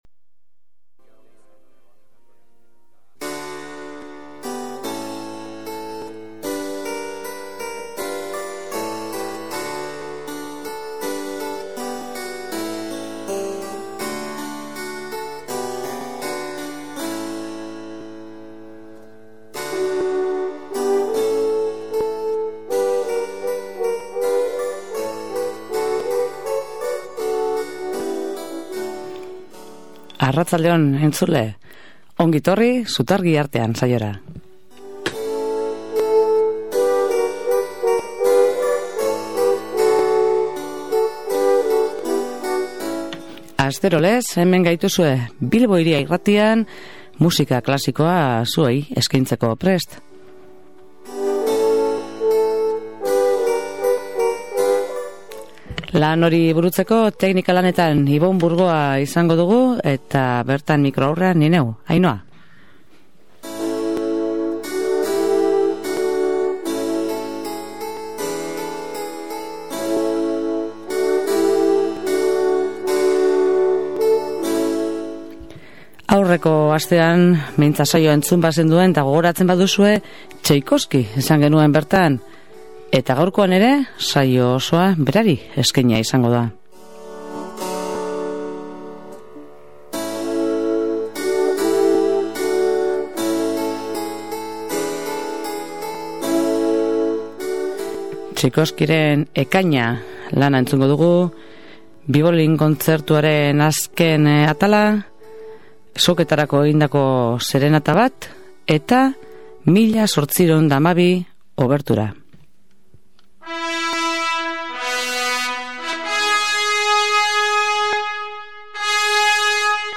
bibolin kontzertuaren azken atala, soketarako egindako serenata bat